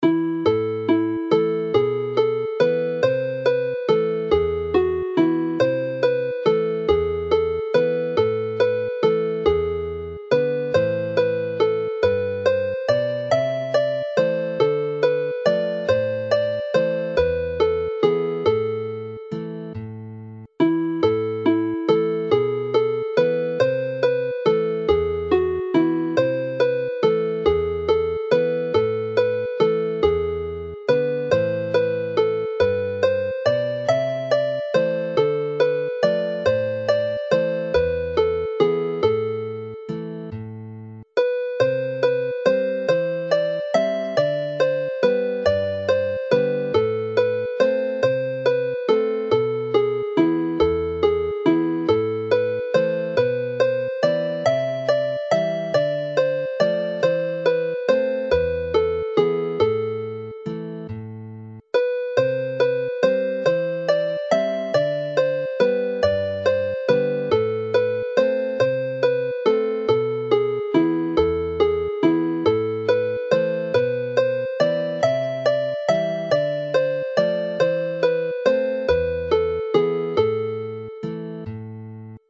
Jig Hyder is a dance tune based on this melody
Play the tune slowly